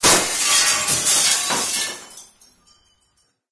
Звук " стекло". Sound " flowed".
Звук разбивающегося стекла.